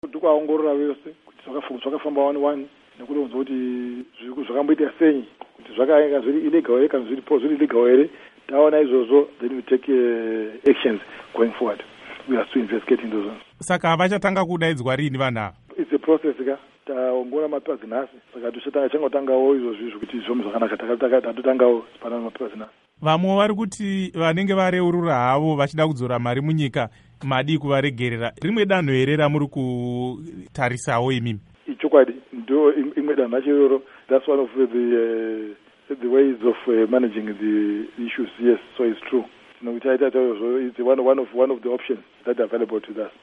Hurukuro naVaJohn Mangudya